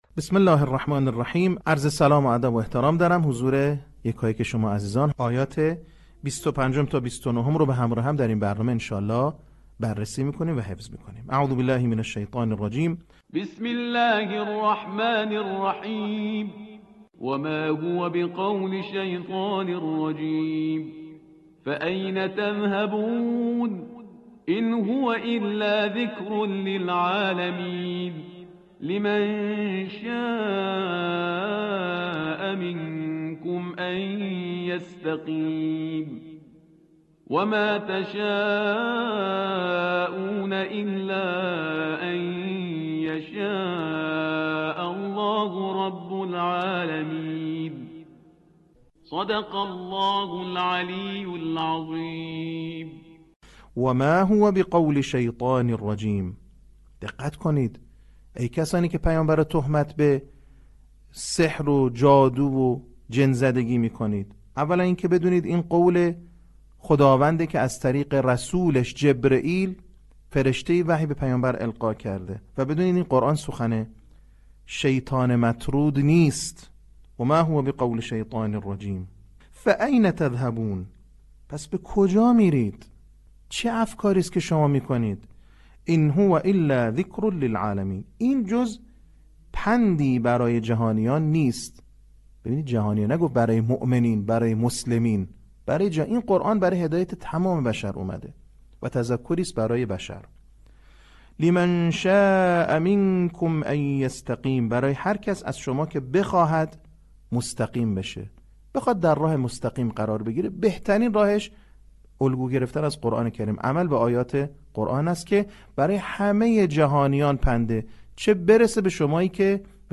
صوت | بخش پنجم آموزش حفظ سوره تکویر